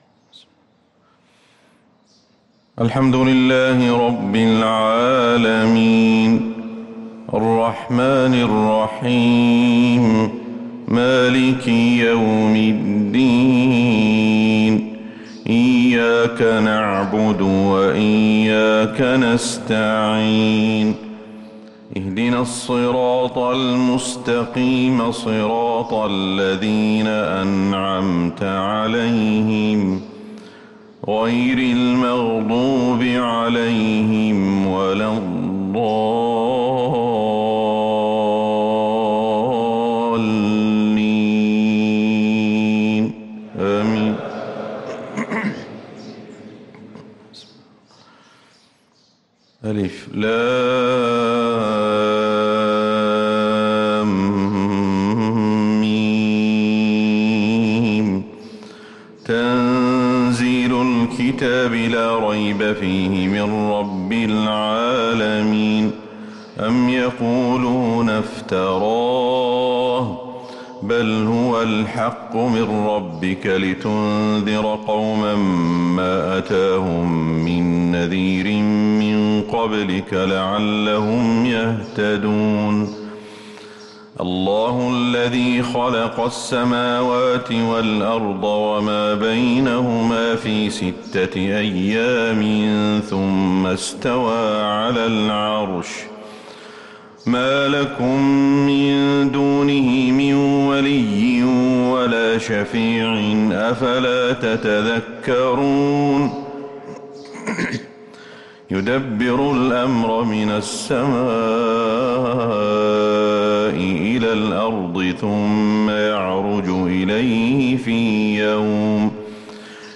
فجر الجمعة 6-2-1444هـ سورتي السجدة و الإنسان | Fajr prayer Surat Alsajdah and Alinsan 2-9-2022 > 1444 🕌 > الفروض - تلاوات الحرمين